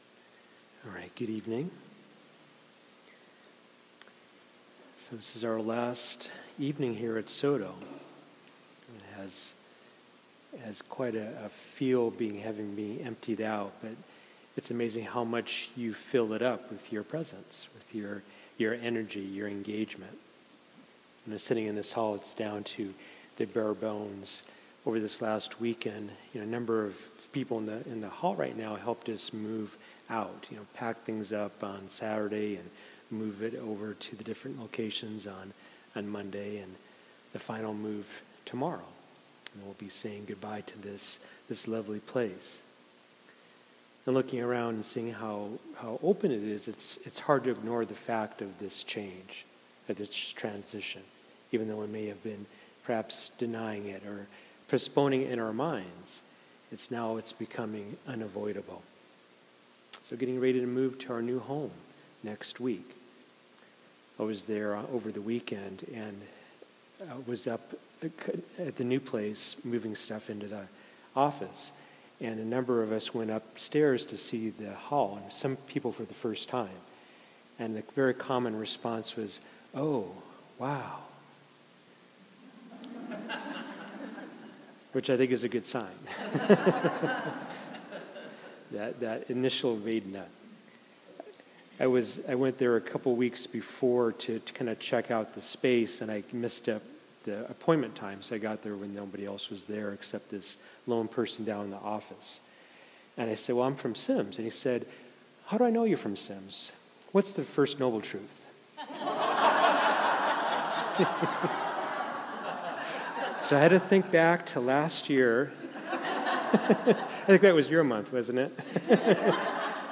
Dharma Talks for Final Sit at SIMS SODO – Seattle Insight Meditation Society
2019-05-28 Venue: Seattle Insight Meditation Center
This was the final sit we had at SIMS SODO.